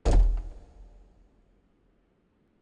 FootstepHandlerMetal1.wav